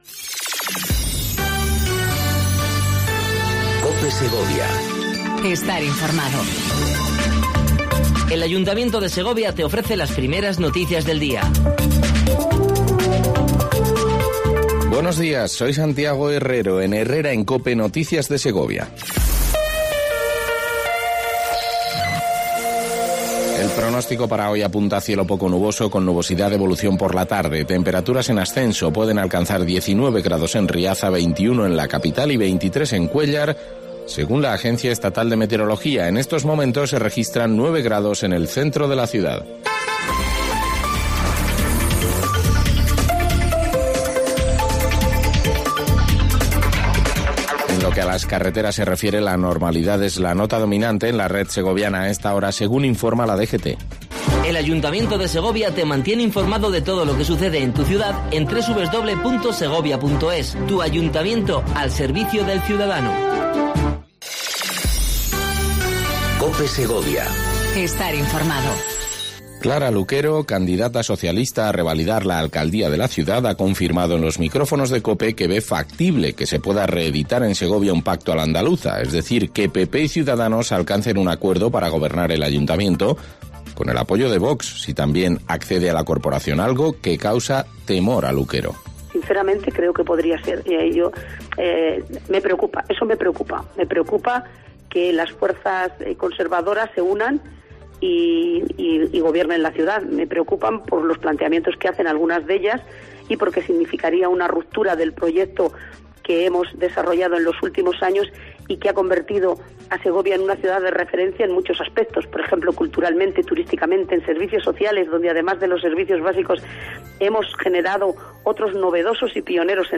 AUDIO: Primer informativo local en cope segovia